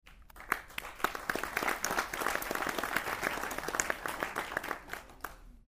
(audience applause)
sfxapplausesmatter.mp3